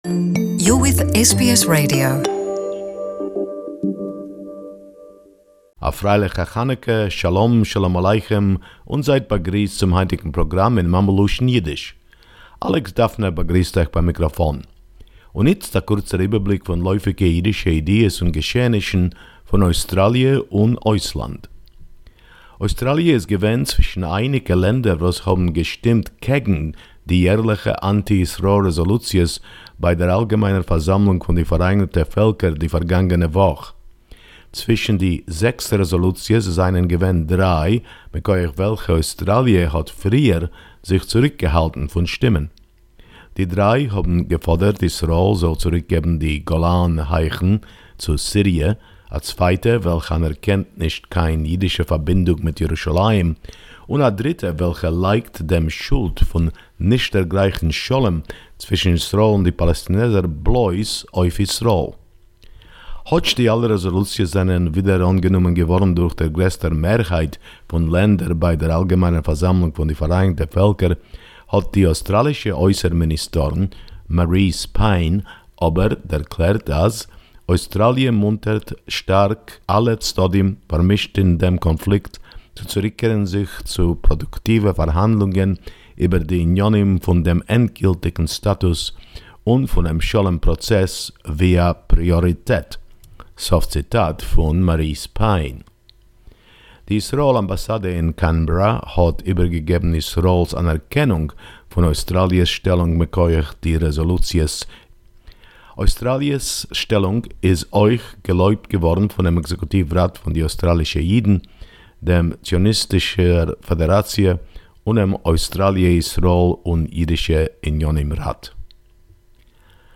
News from the Jewish world as for the 9th of December 2018